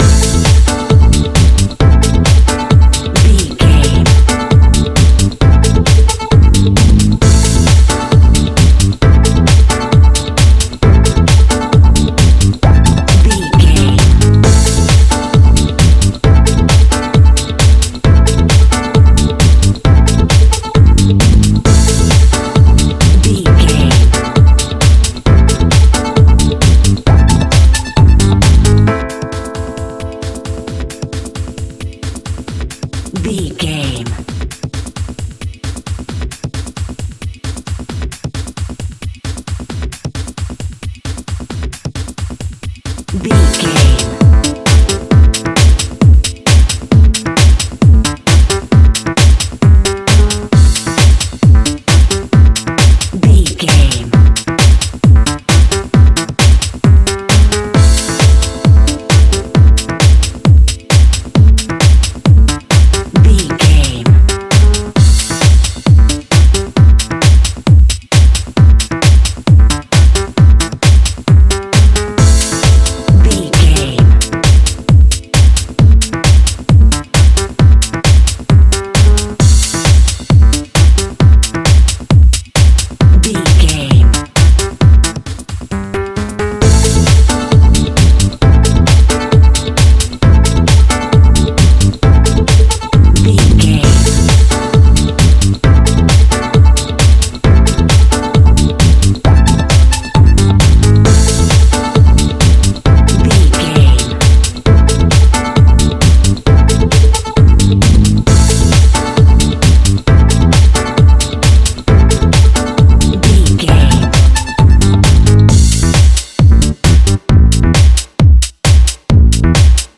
Funky Dance Music.
Atonal
Fast
groovy
smooth
uplifting
drum machine
synthesiser
bass guitar
post disco
electro house
happy